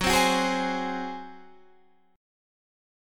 F# Suspended 2nd Flat 5th